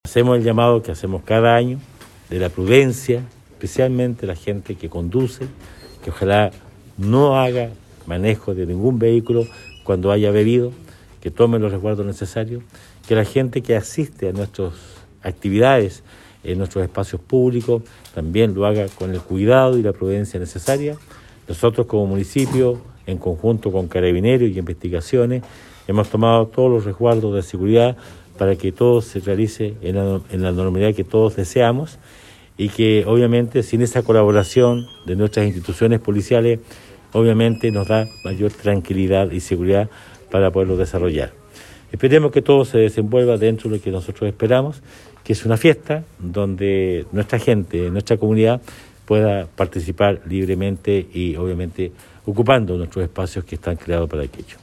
El Alcalde Emeterio Carrillo realizó un llamado a la comunidad osornina para celebrar con responsabilidad, principalmente con el objetivo de evitar accidentes de tránsito por la ingesta de alcohol.